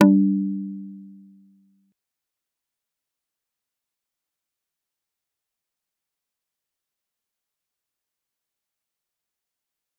G_Kalimba-G3-pp.wav